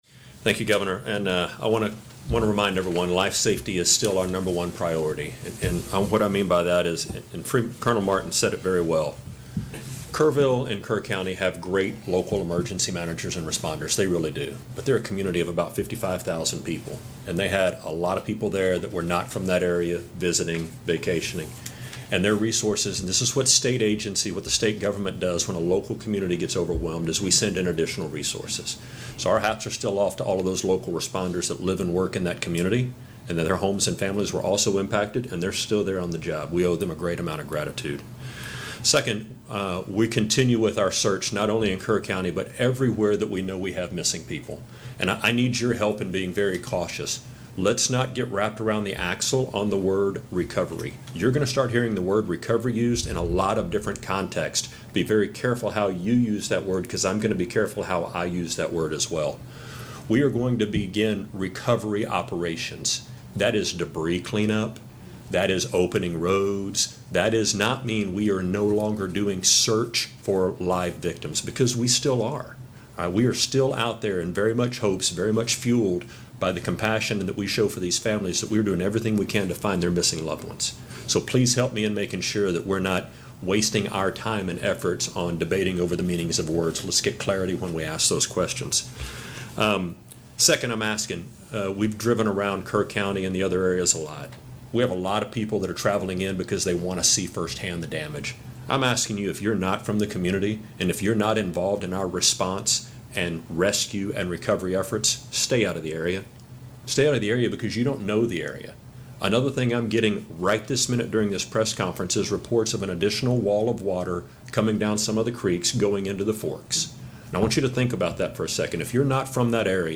TDEM Chief Nim Kidd
delivered 6 July 2025, Austin, Texas
Audio mp3 of Address       Audio AR-XE mp3 of Address